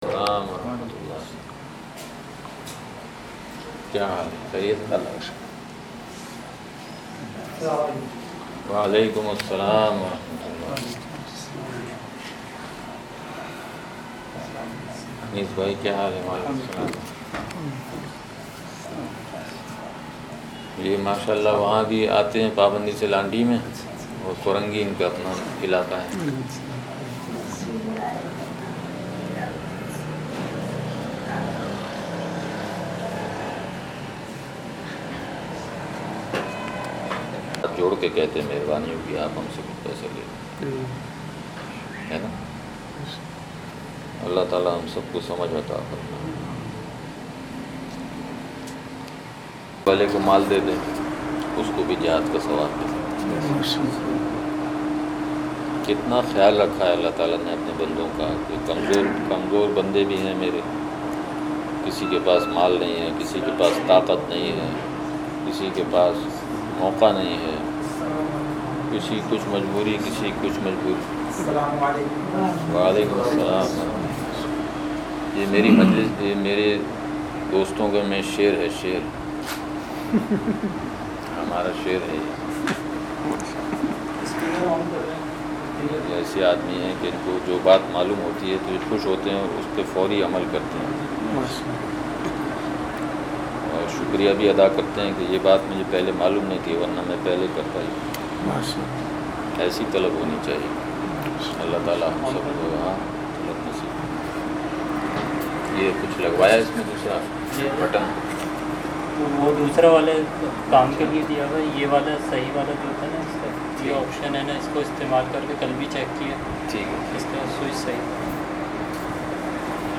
بیان